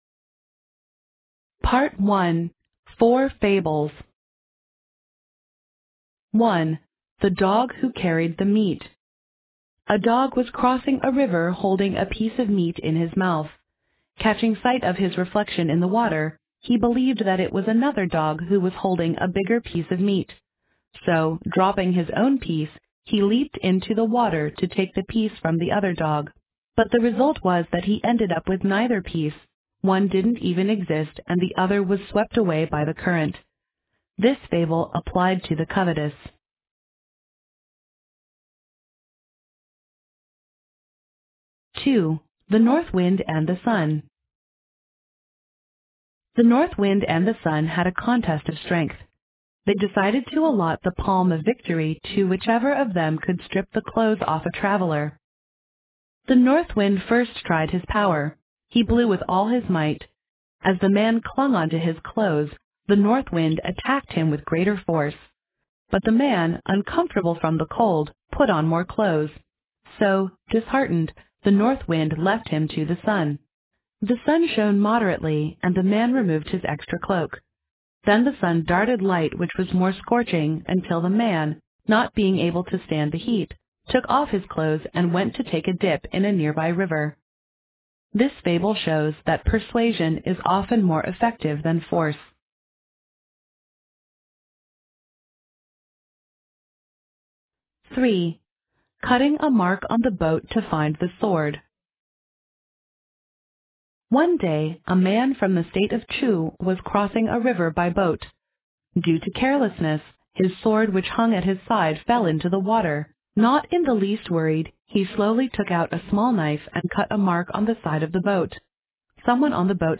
在线英语听力室英文短篇寓言故事的听力文件下载,英语有声读物-在线英语听力室